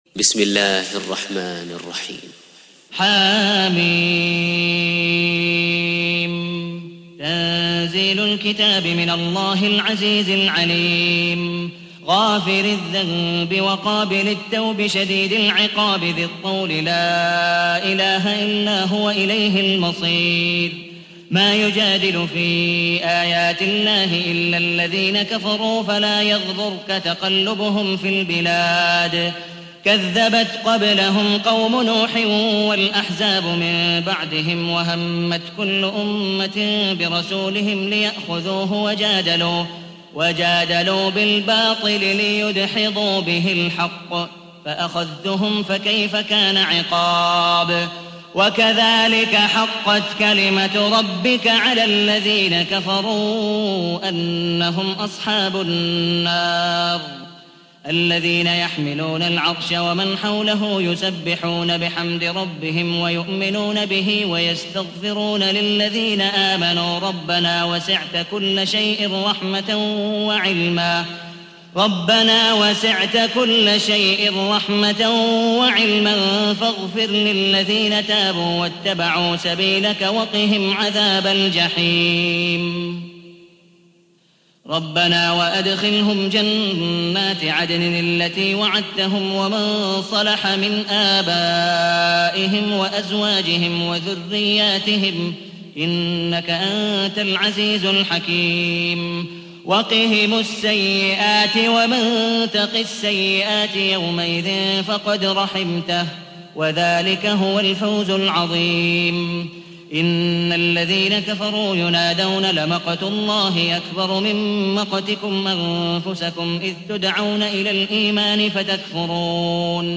تحميل : 40. سورة غافر / القارئ عبد الودود مقبول حنيف / القرآن الكريم / موقع يا حسين